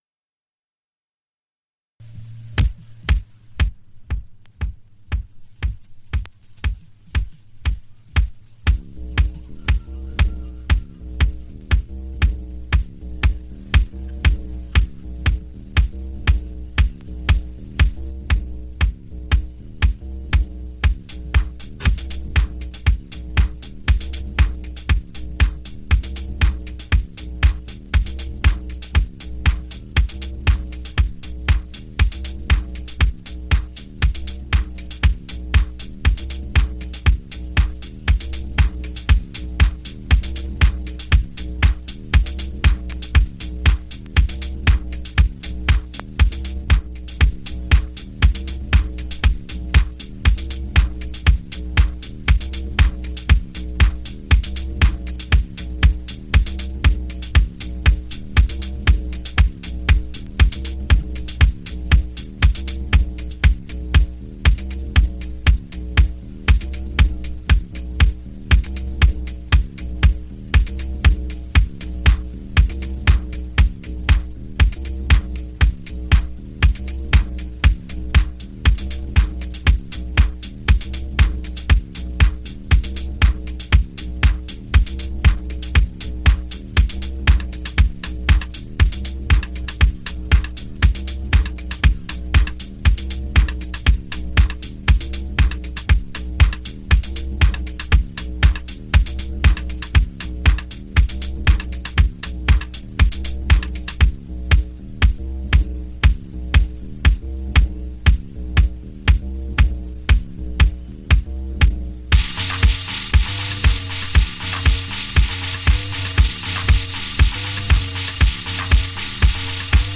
Raw house cuts
late 80s original Chicago house jams